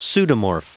Prononciation du mot pseudomorph en anglais (fichier audio)
Prononciation du mot : pseudomorph